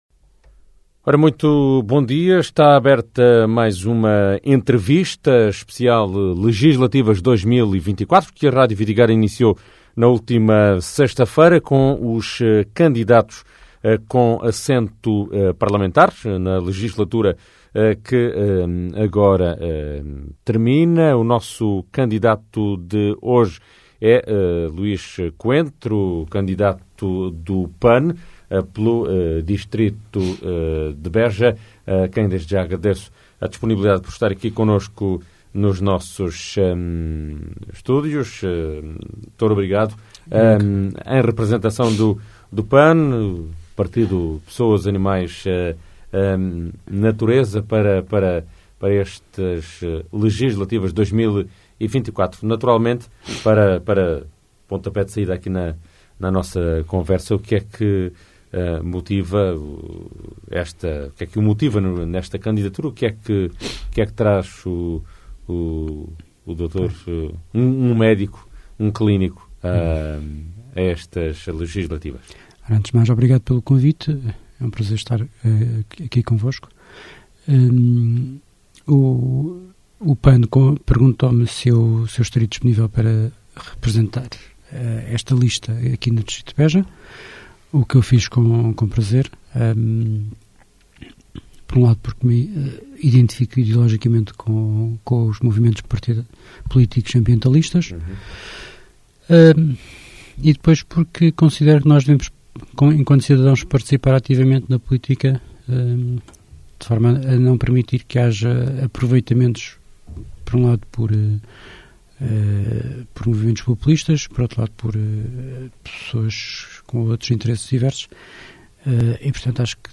A entrevista com o cabeça de lista do PAN